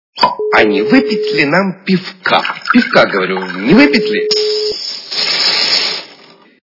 » Звуки » Люди фразы » Голос - А не выпить ли нам пивка
При прослушивании Голос - А не выпить ли нам пивка качество понижено и присутствуют гудки.
Звук Голос - А не выпить ли нам пивка